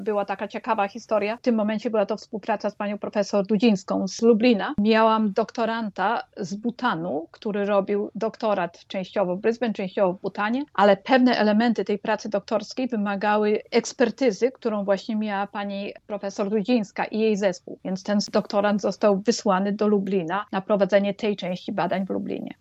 W rozmowie z nami przed dwoma laty mówiła o wspólnych badaniach z naukowcami z Lublina i prowadzeniu doktorantów.